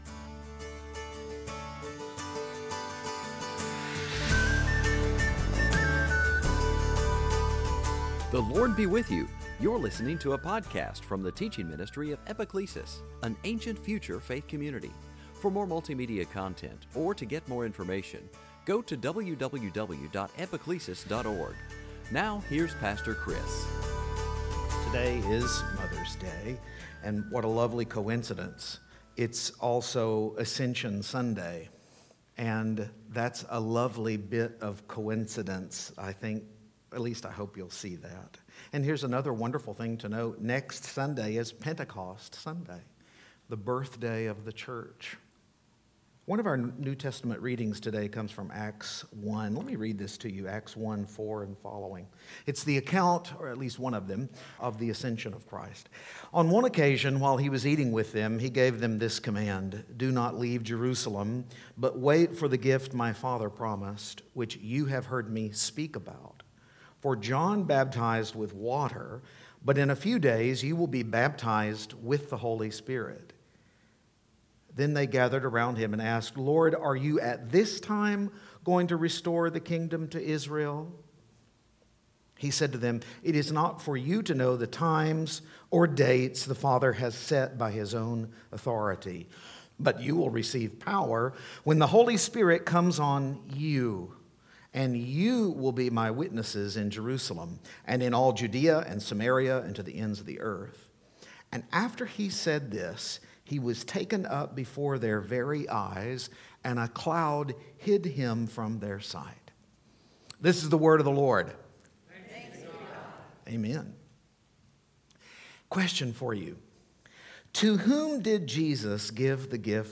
Series: Sunday Teaching
Service Type: Ascension Sunday